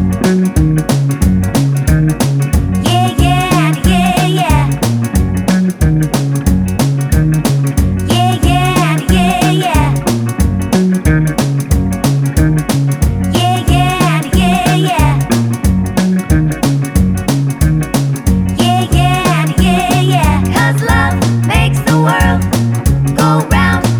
Crooners